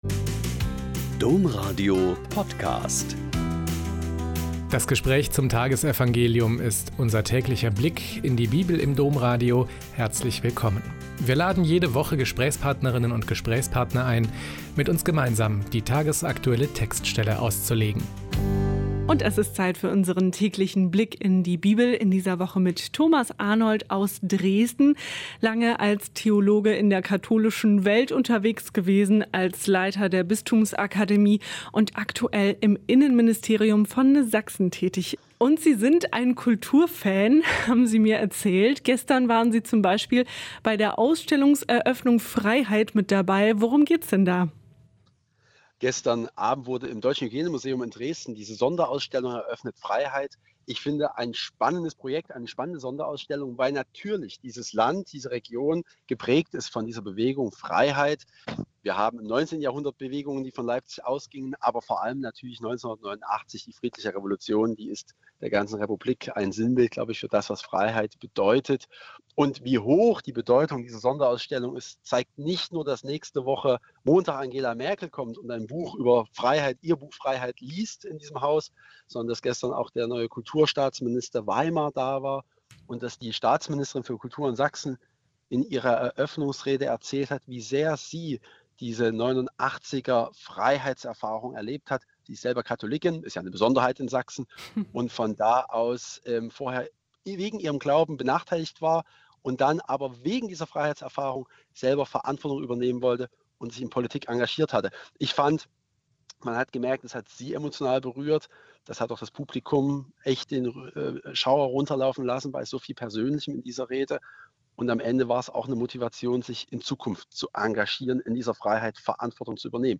Mt 6,19-23 - Gespräch